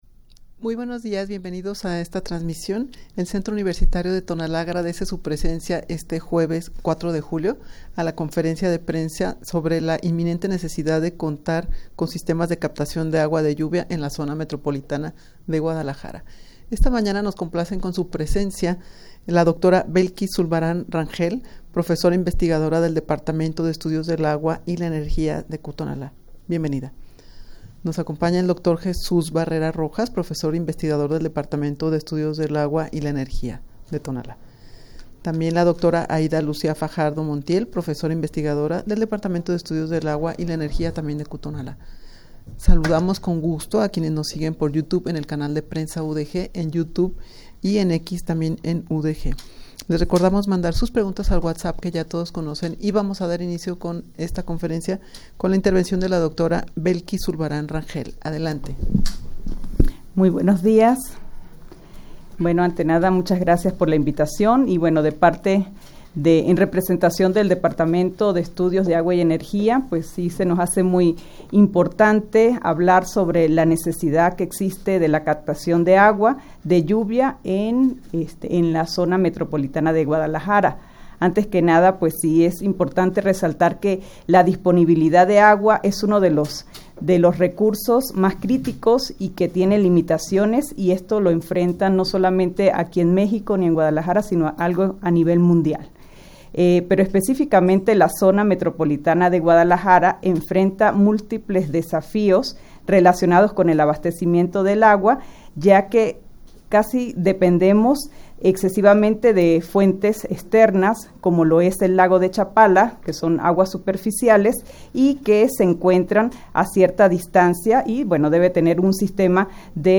rueda-de-prensa-sobre-la-inminente-necesidad-de-contar-con-sistemas-de-captacion-de-agua-de-lluvia-en-la-zmg.mp3